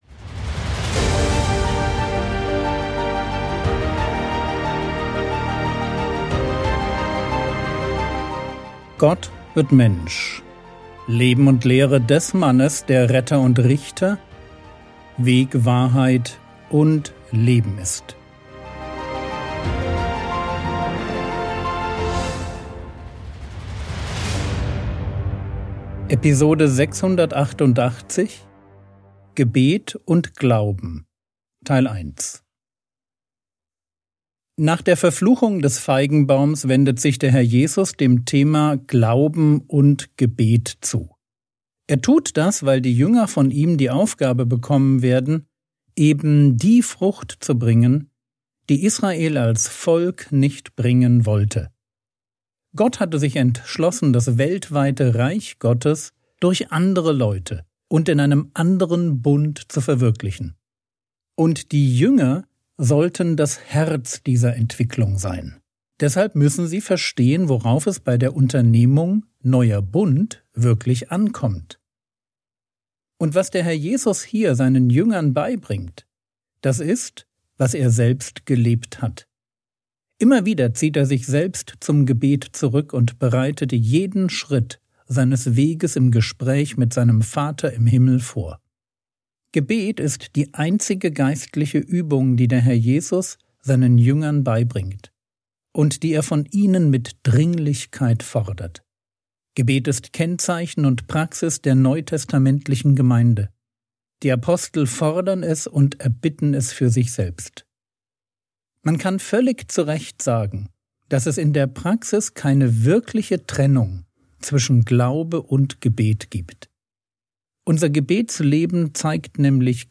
Episode 688 | Jesu Leben und Lehre ~ Frogwords Mini-Predigt Podcast